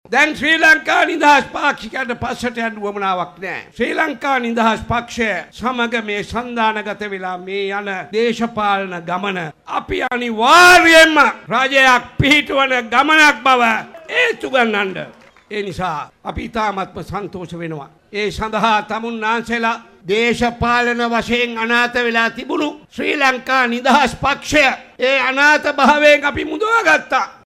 තම පක්ෂය ප්‍රමුඛ සන්ධානය මගින් ඉදිරි ජනාධිපතිවරයා තීරණය කරන බවයි අම්බලන්තොට ප්‍රදේශයේ ඊයේ පැවතී ජන හමුවට එක් වෙමින් අමත්‍ය නිමල් සිරිපාල ද සිල්වා මහතා සඳහන් කලේ.